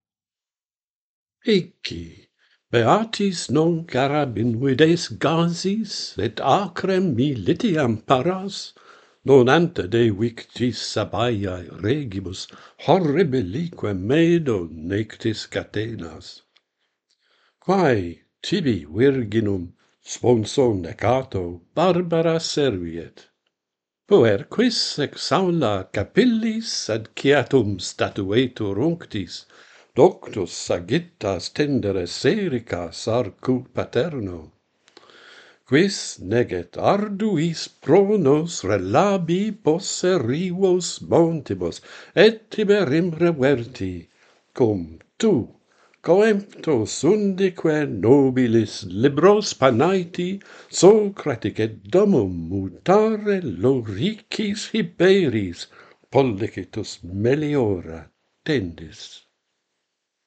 Iccius goes soldiering - Pantheon Poets | Latin Poetry Recited and Translated